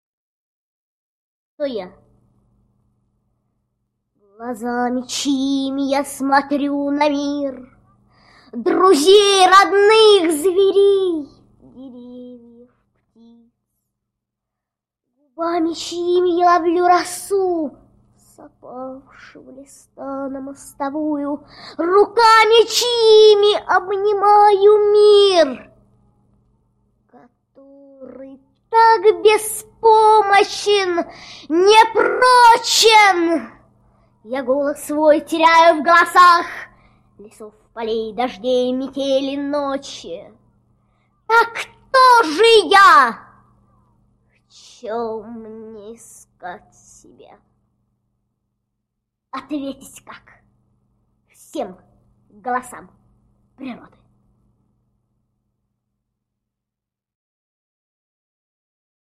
4. «Ника Турбина – Кто я? (читает автор)» /
nika-turbina-kto-ya-chitaet-avtor